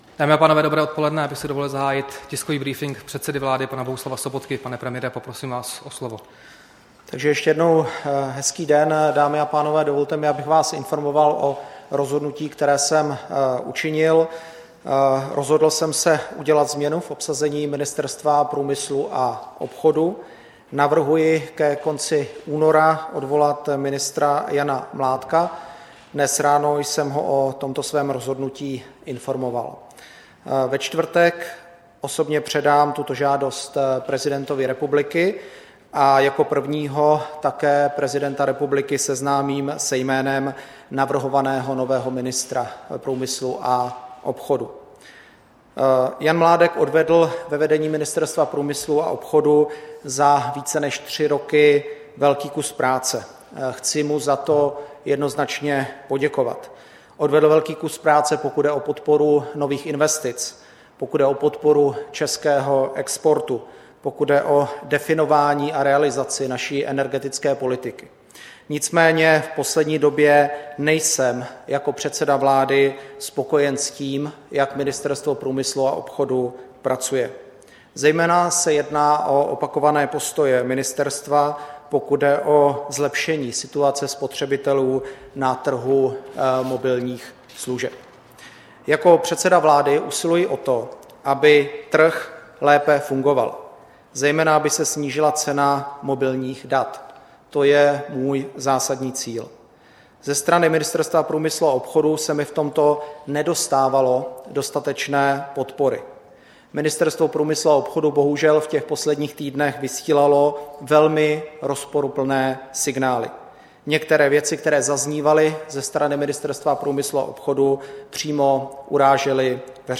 Tisková konference předsedy vlády Bohuslava Sobotky k odvolání ministra průmyslu a obchodu Jana Mládka, 20. února 2017